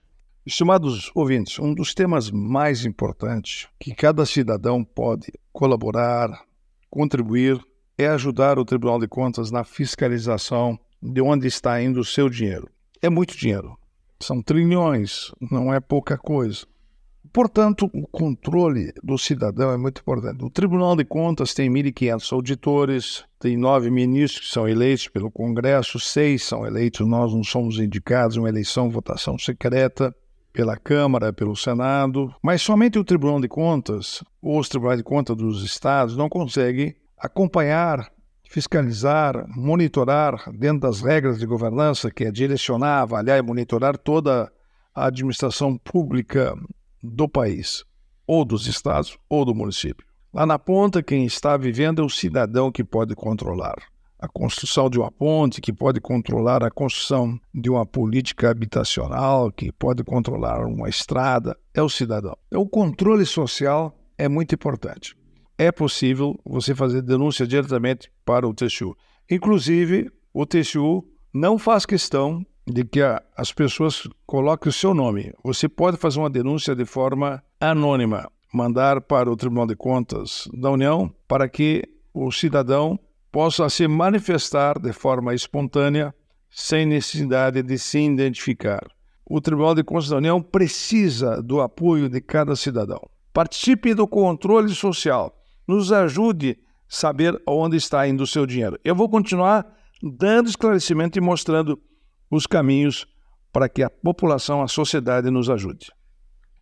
É o assunto do comentário do ministro do Tribunal de Contas da União, Augusto Nardes, desta quinta-feira (11/07/24), especialmente para OgazeteirO.